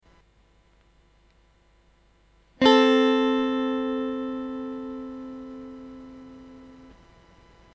A 6th is where the 2 notes are played 6 notes apart from each other in a scale.
Guitar 6th Double Stop